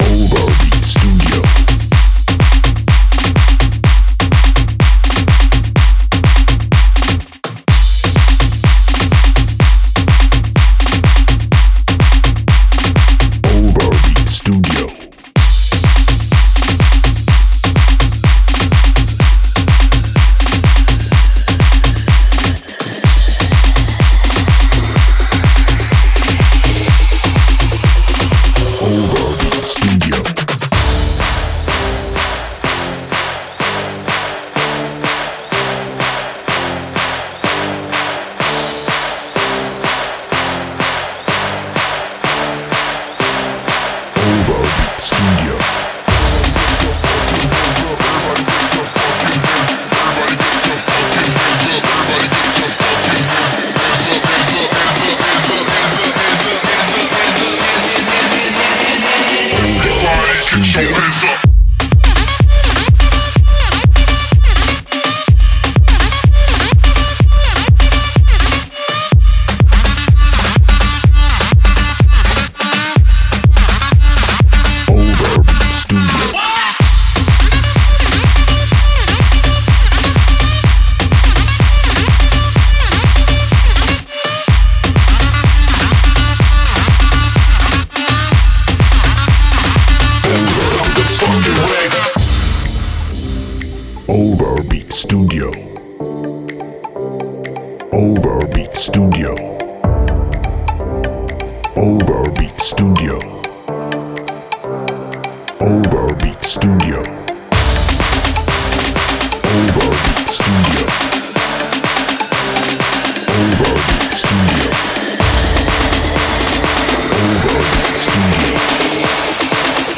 La Track include una Acapella royalty free.